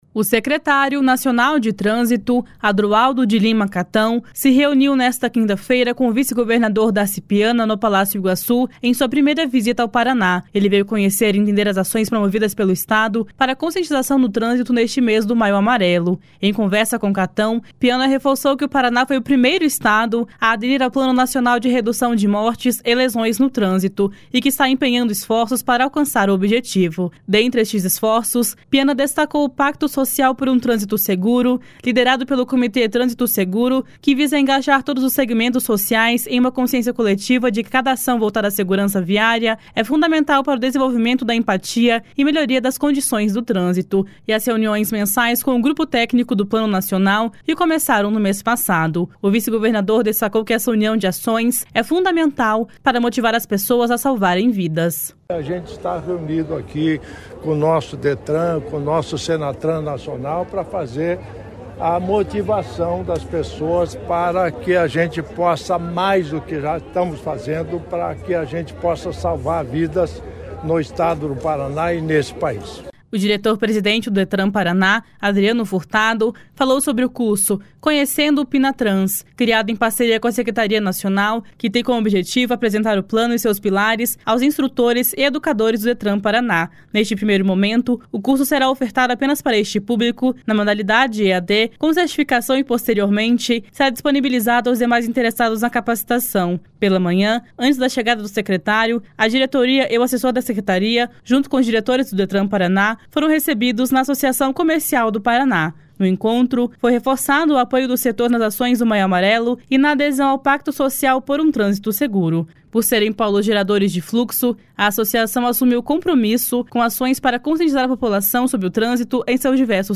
O vice-governador destacou que essa união de ações é fundamental para motivar as pessoas a salvarem vidas. // SONORA DARCI PIANA //